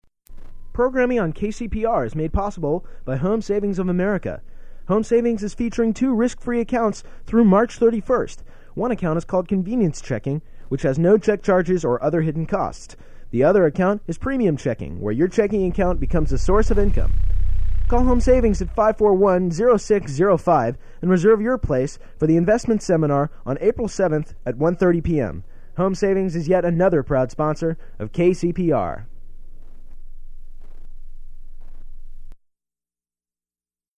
Home Savings [advertisement]
Form of original Audiocassette